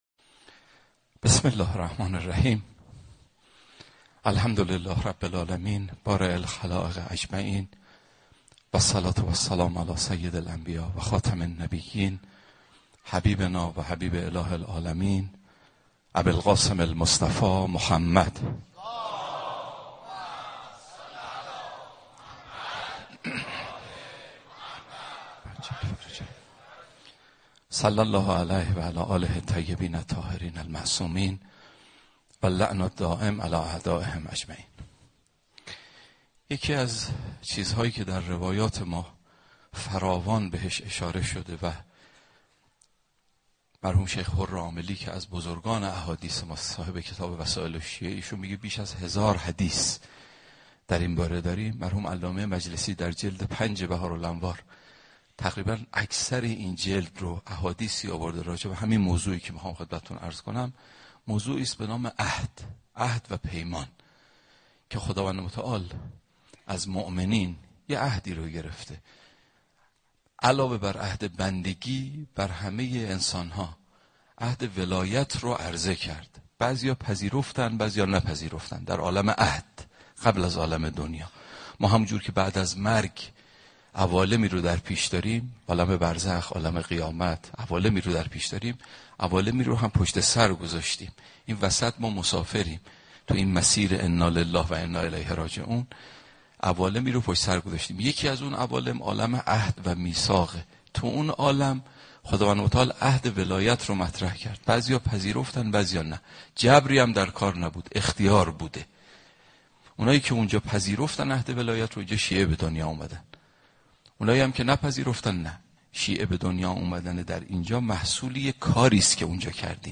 صوت سخنرانی مذهبی و اخلاقی خداوند متعال به بندگان خود علاوه بر عهد بندگی، عهد ولایت را نیز عرضه کرد.